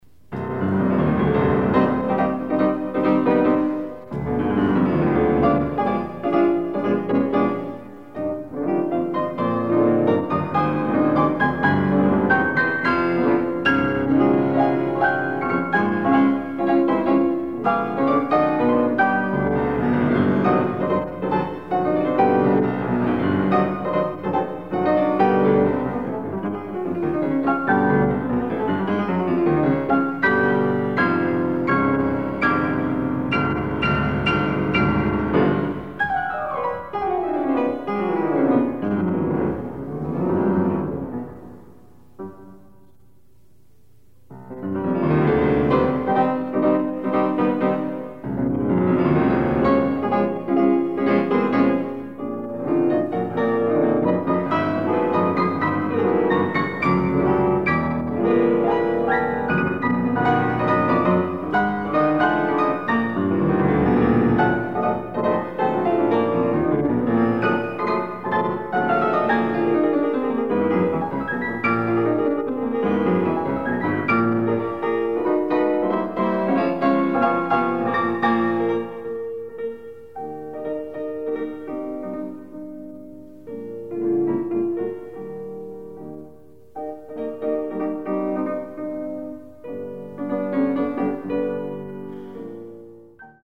Additional Date(s)Recorded September 12, 1977 in the Ed Landreth Hall, Texas Christian University, Fort Worth, Texas
SubjectEtudes
Suites (Piano)
Sonatas (Piano)
Short audio samples from performance